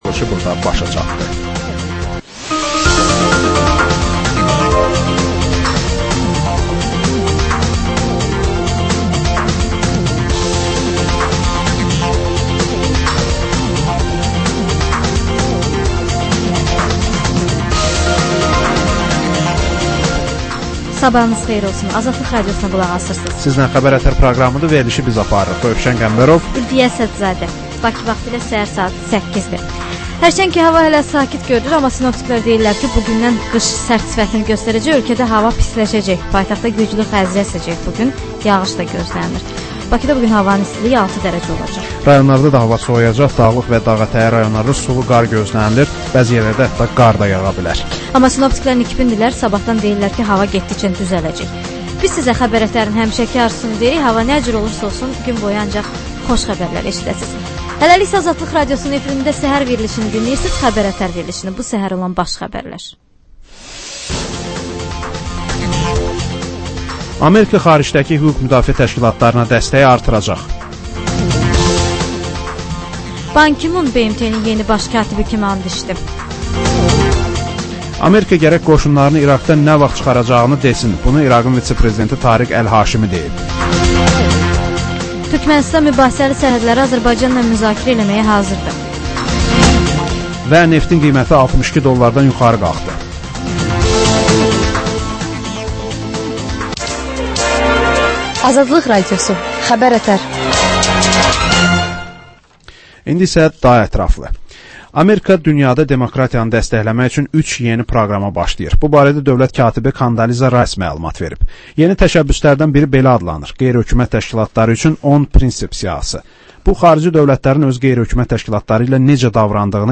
Xəbərlər
Xəbər, reportaj, müsahibə.